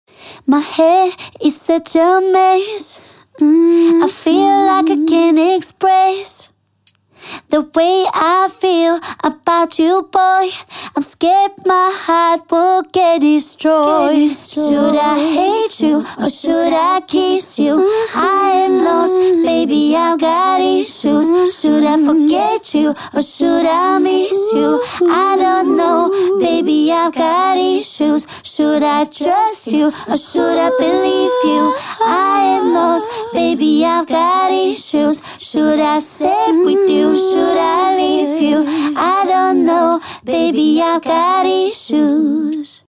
除了完整的无伴奏合唱之外，您还将获得更多漂亮的广告素材，谐音单音和短语。